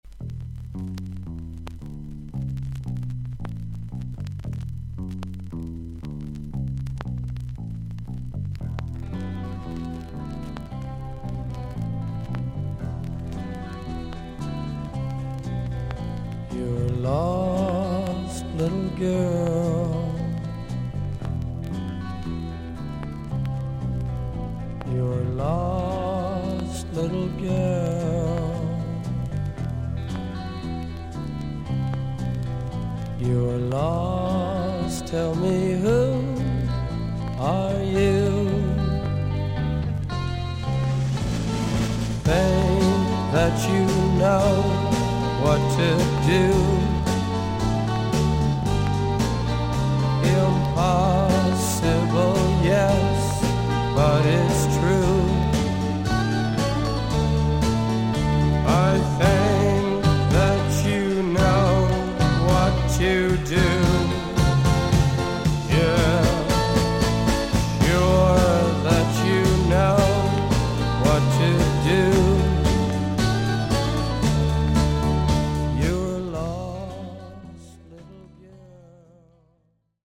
アメリカ盤 / 12インチ LP レコード / ステレオ盤
B1序盤1分くらいまでキズによるパチノイズあり。
少々サーフィス・ノイズあり。クリアな音です。